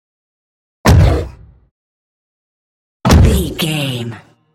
Monster creature puf hit
Sound Effects
heavy
intense
dark
aggressive